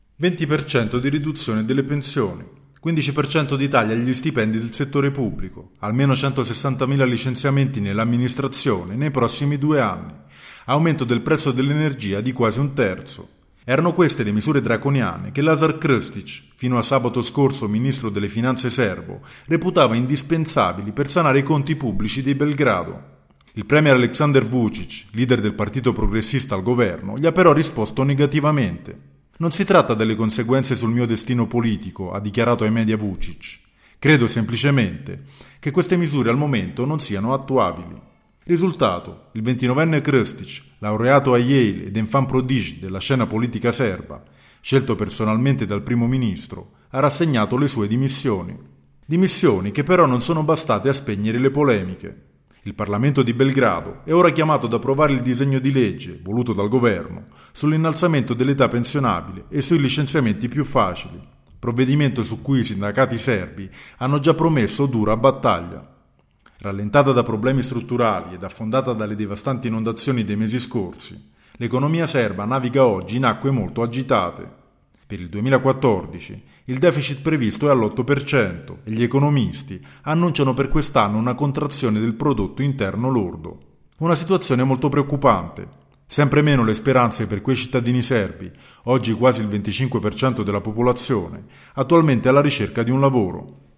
Si infuoca in Serbia il dibattito sulle misure di austerità volute dal governo Vučić per traghettare il paese fuori dalla recessione. Visioni divergenti hanno portato alle dimissioni del giovanissimo ministro delle Finanze Lazar Krstić. Il servizio